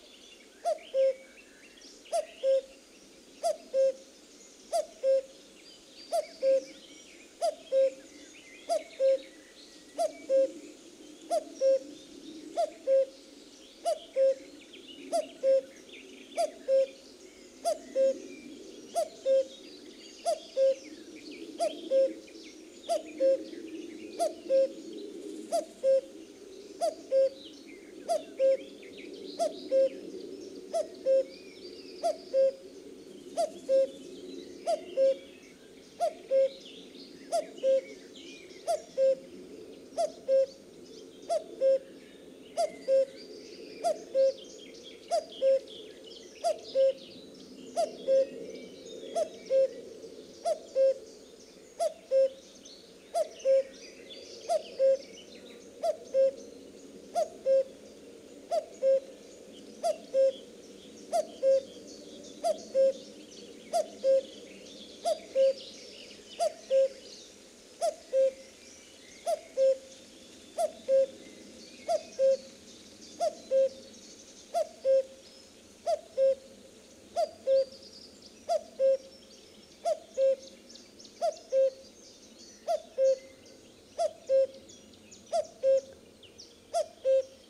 На этой странице собраны натуральные звуки кукушки, которые можно скачать или слушать онлайн.
Звук кукушки среди деревьев — 1 вариант